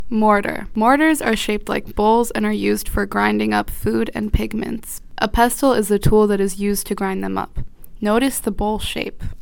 The audio guide to the kits is provided below.